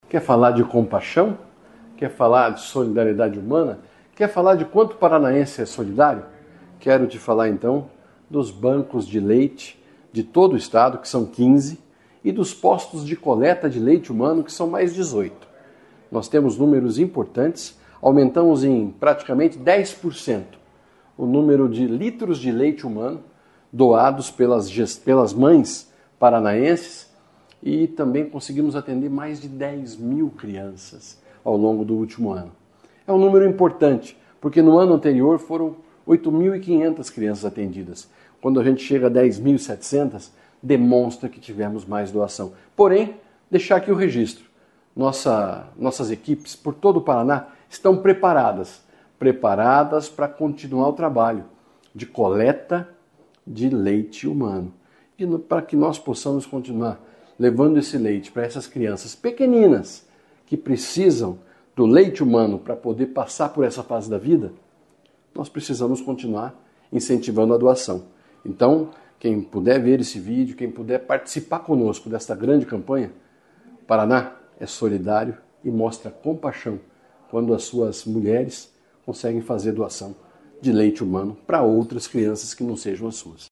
Sonora do secretário Estadual da Saúde, Beto Preto, sobre o aumento na coleta de leite humano e de crianças atendidas no Paraná